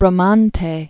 (brə-mäntā, brä-mäntĕ), Donato 1444-1514.